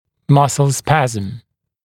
[‘mʌsl ‘spæzəm][‘масл ‘спэзэм]мышечный спазм